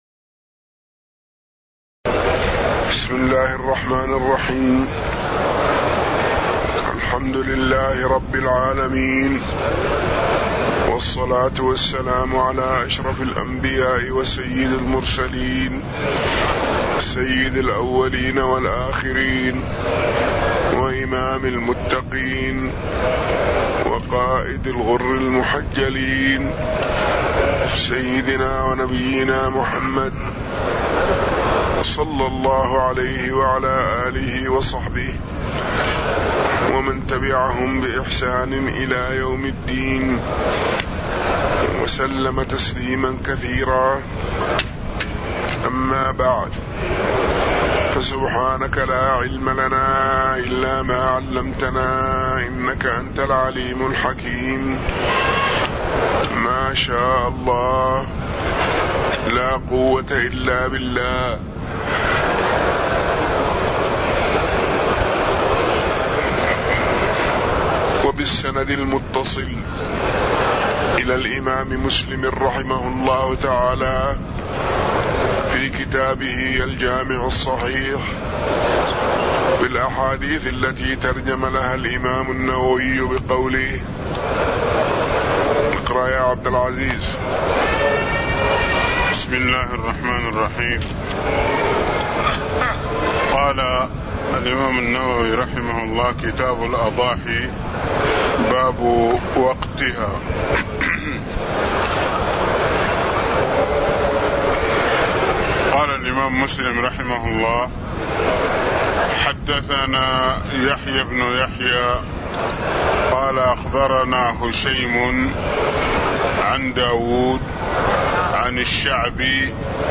تاريخ النشر ١٧ جمادى الأولى ١٤٣٦ هـ المكان: المسجد النبوي الشيخ